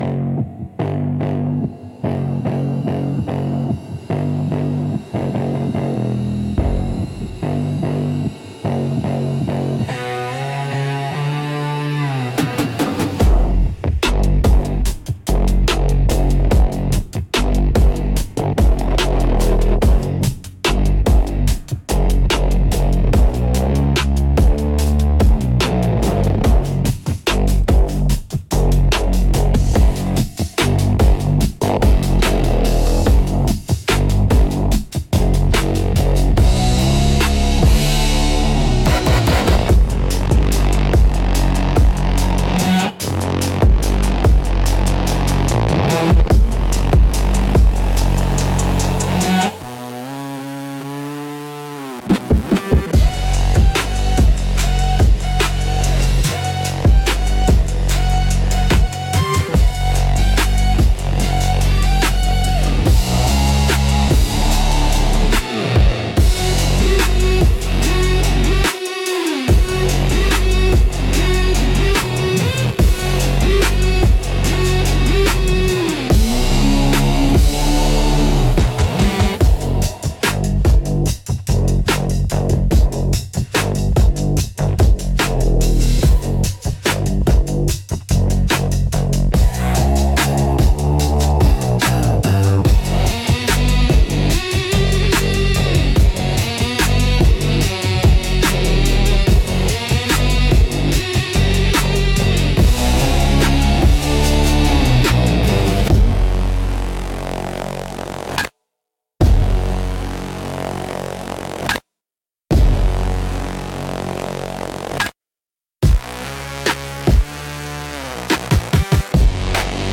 Instrumental - Southern Gothic x Dark Pop Fusion